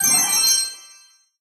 get_coins_01.ogg